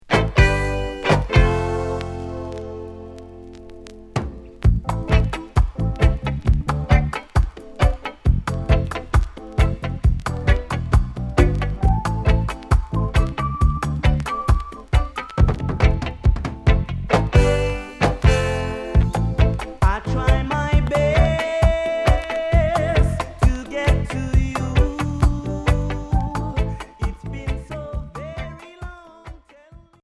Reggae Unique 45t